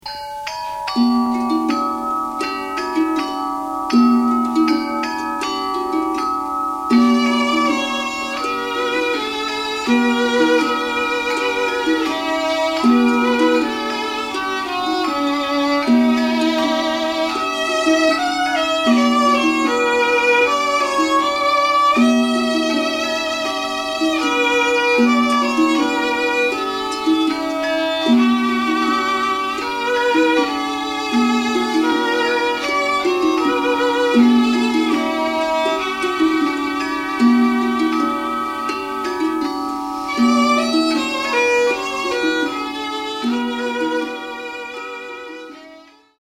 キーワード：空想民俗　創作楽器 　ガムラン　Harry Partch